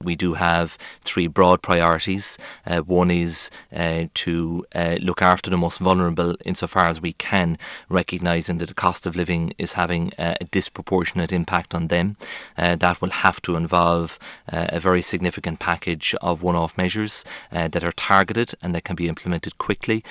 Minister Michael McGrath says his focus will also be helping on those on lower incomes….